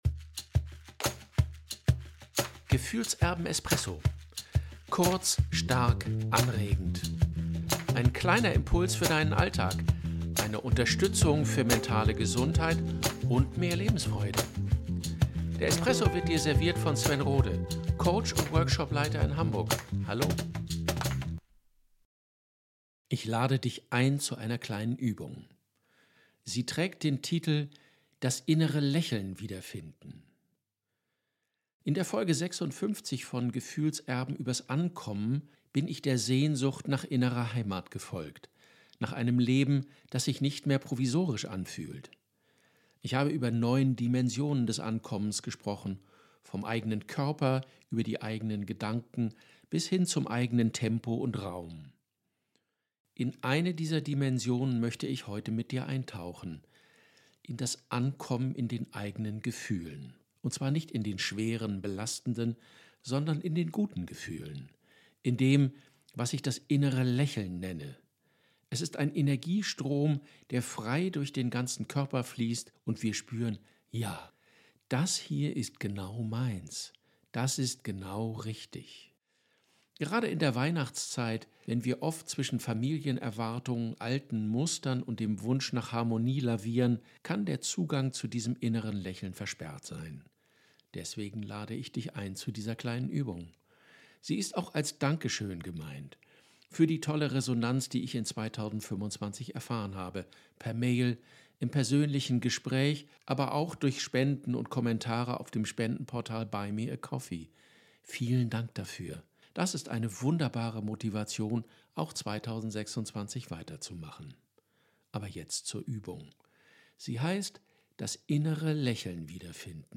Eine angeleitete Schreibübung: "Das innere Lächeln finden"